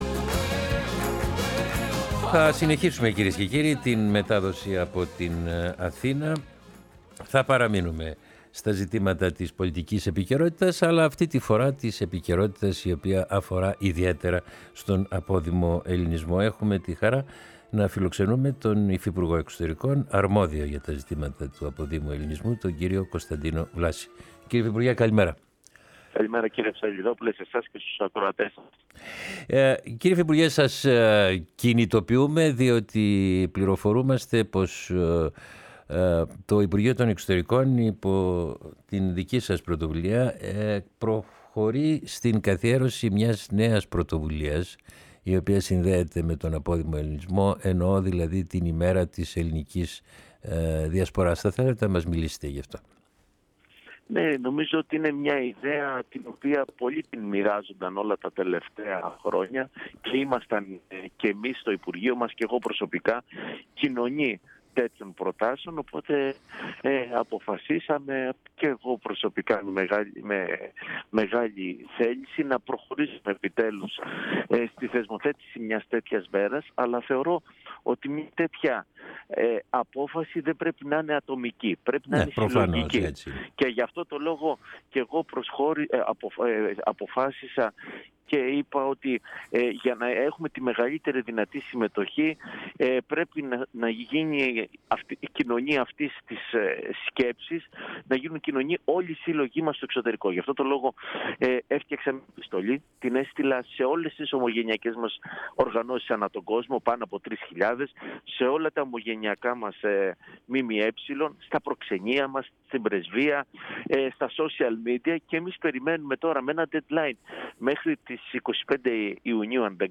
Ο ΥΦΥΠΕΞ Κώστας Βλάσης στη “Φωνή της Ελλάδας” για την Παγκόσμια Ημέρα της Ελληνικής Διασποράς